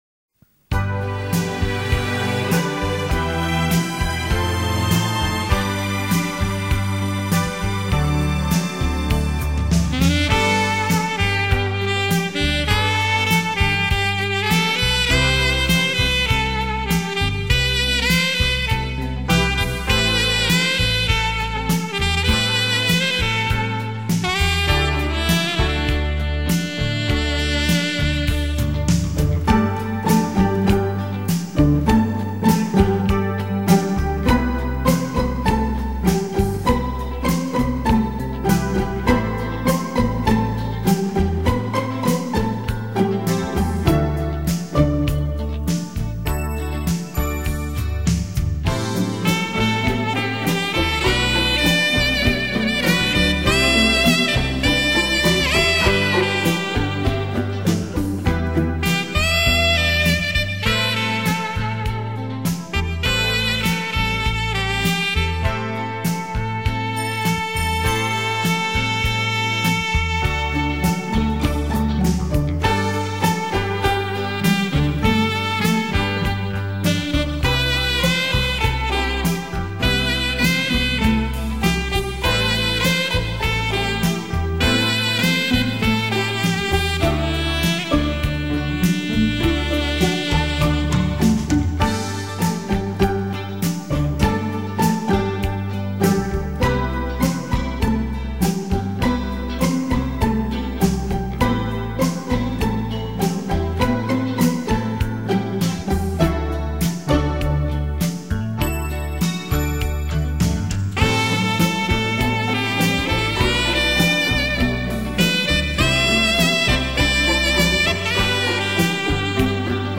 质朴的萨克斯风与悠扬的弦乐
陶醉在萨克斯风JAZZ风味的时尚里
兼具古典与流行的声音美学